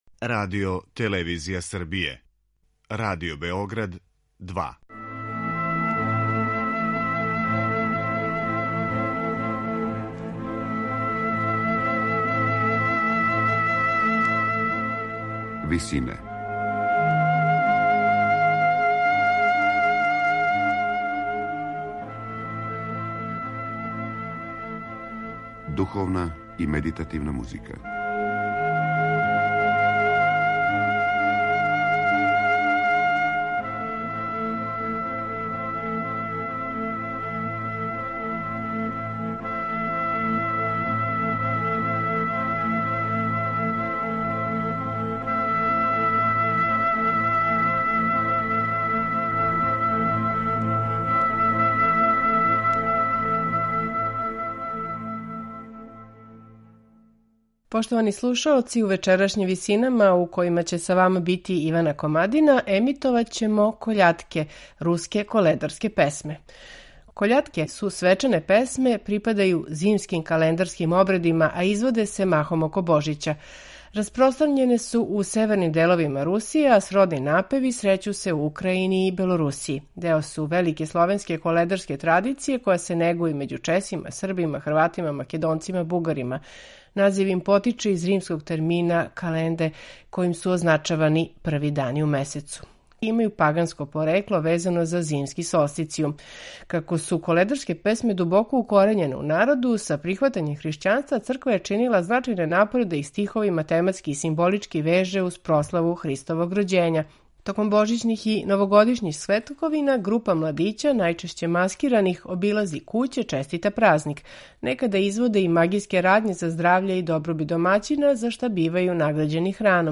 Aрхијерејски хор Саборног храма Светог Симеона из Чељабинка
Руске коледарске песме